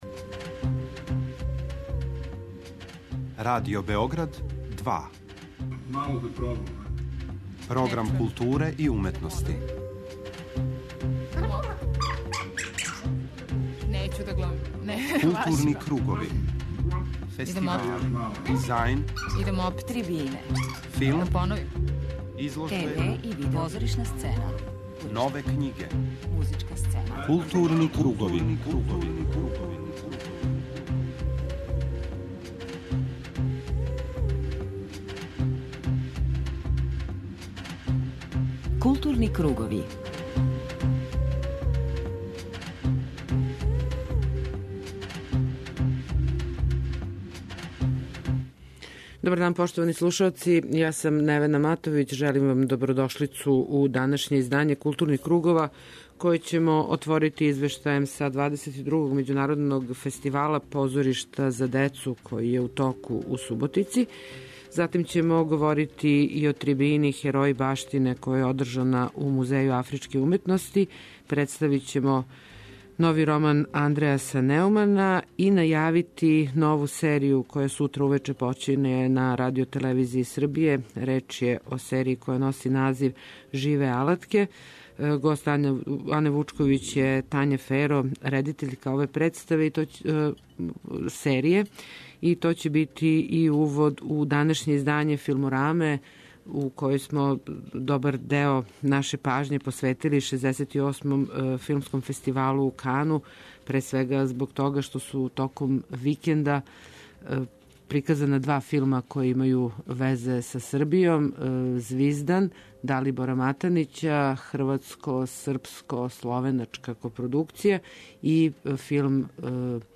преузми : 52.52 MB Културни кругови Autor: Група аутора Централна културно-уметничка емисија Радио Београда 2.